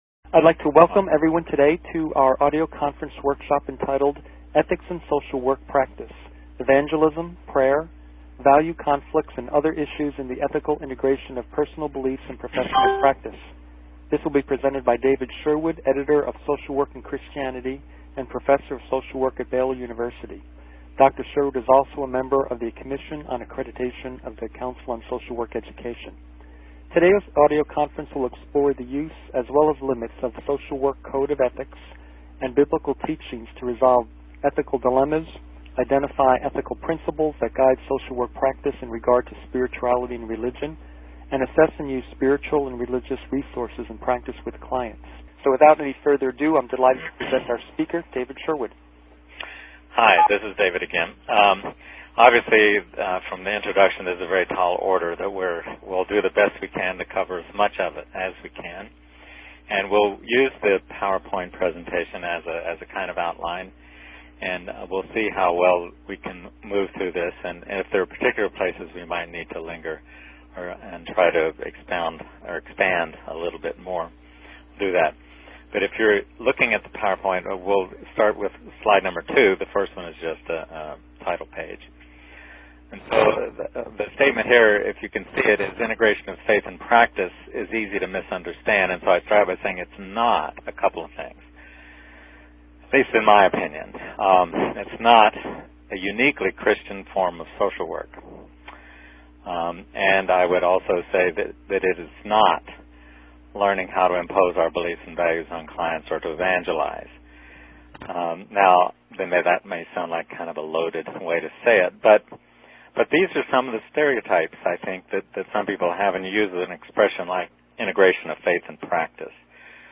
Training format: audio-based